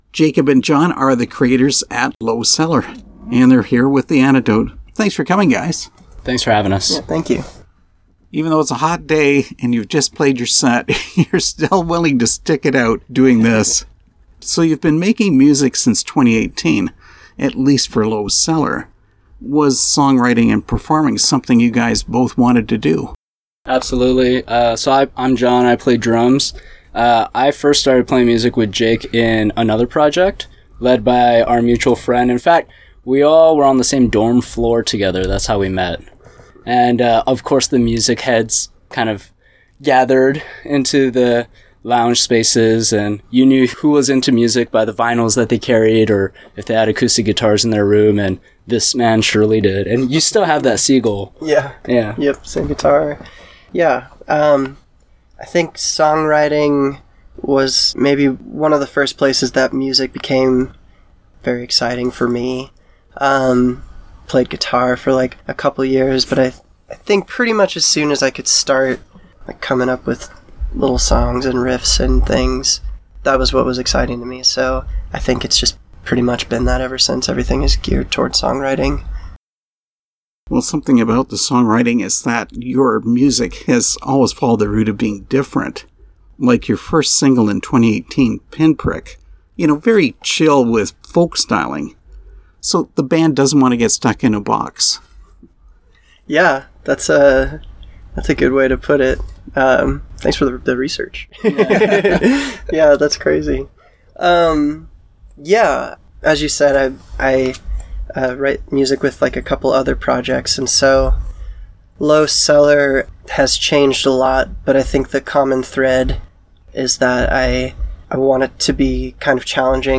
Interview with Lowe Cellar
lowe-cellar-interview.mp3